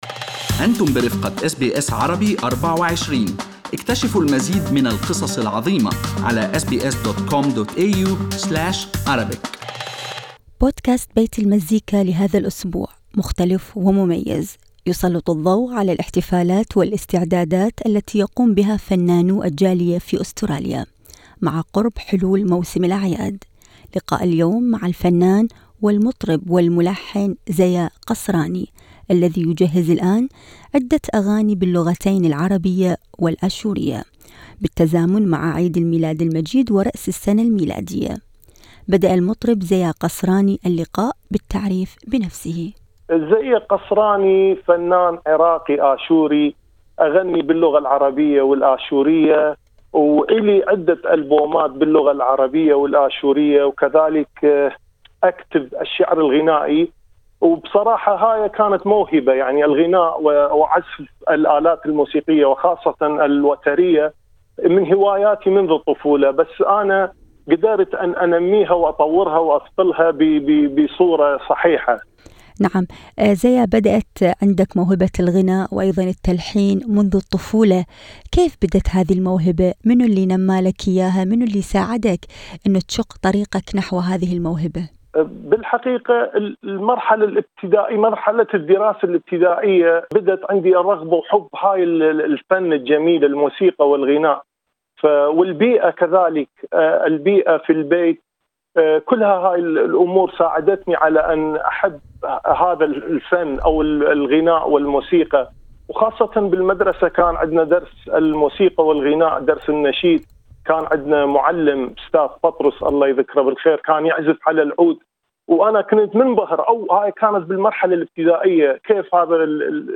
لقاء اليوم مع الفنان والمطرب والملحن